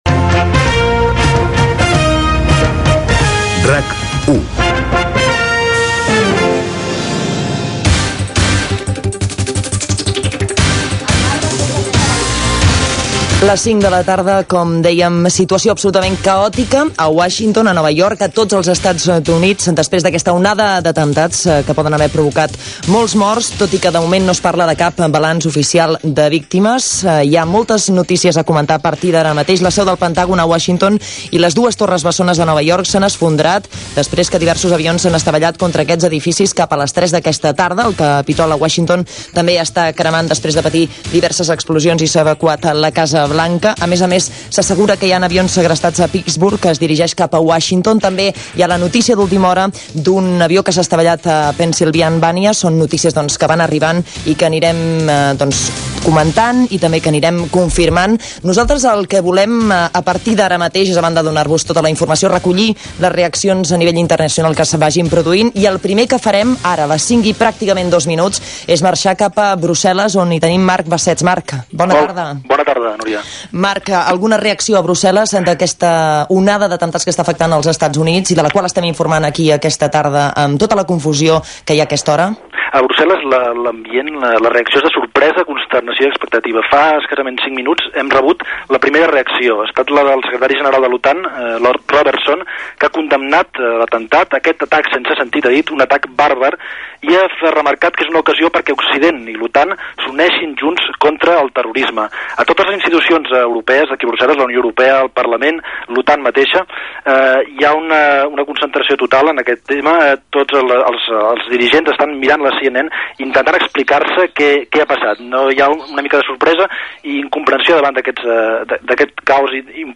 Indicatiu de l'emissora, hora, atemptats amb avions als EE.UU. a Washington i Nova York. Connexió amb Brusel·les amb reaccions de responsables de l'OTAN i Londres, amb reaccions del govern britànic
Informatiu
FM